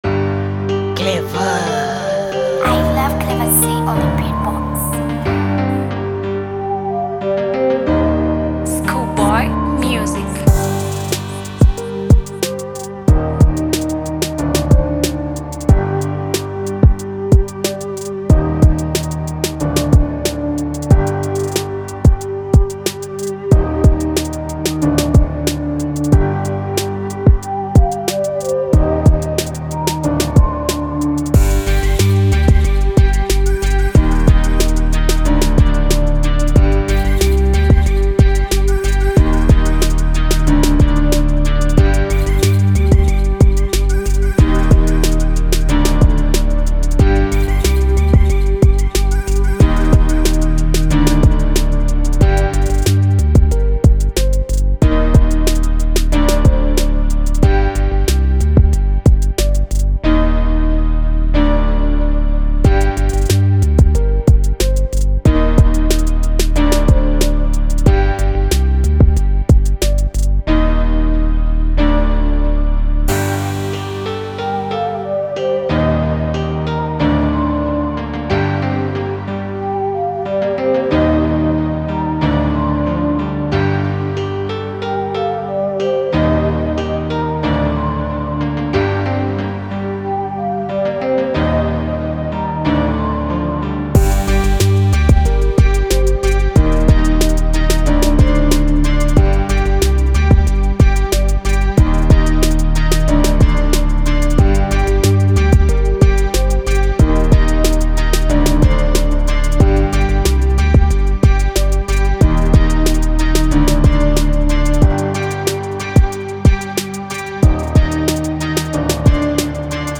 FREE BEATS